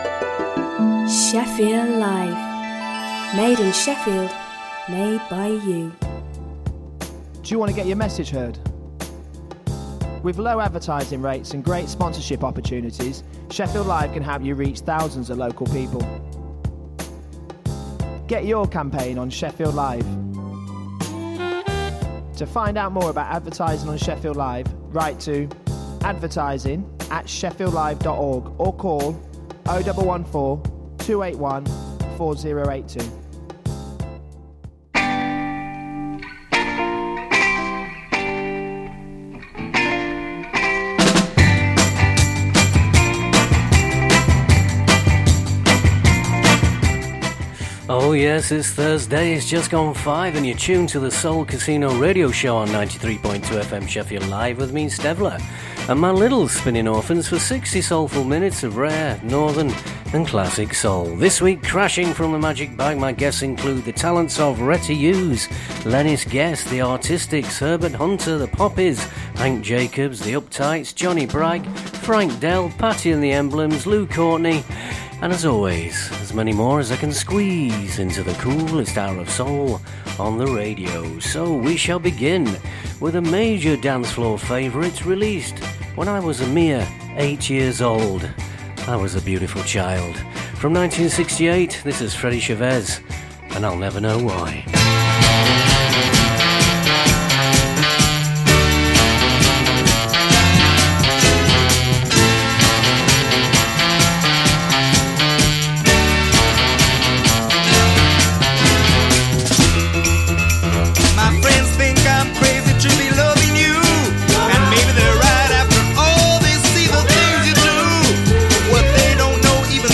Sixty soulful minutes of rare, northen and classic soul.